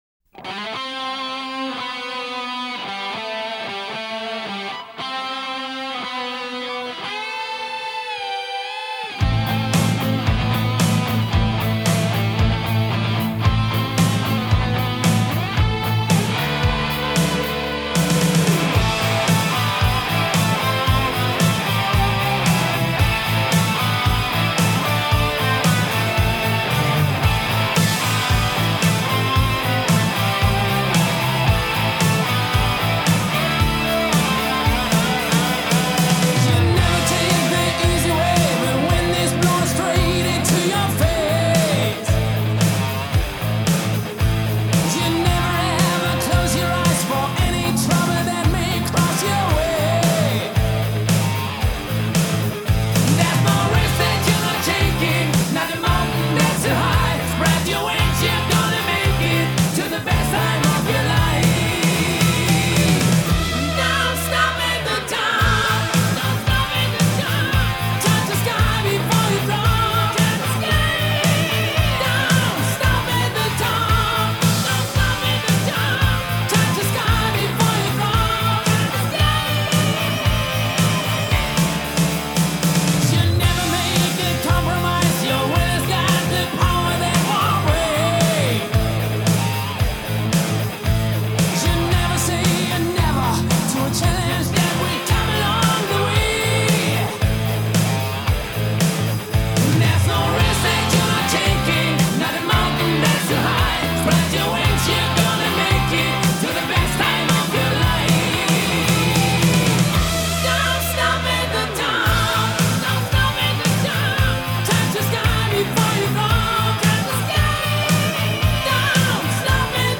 At first, I was a little dismayed by the production.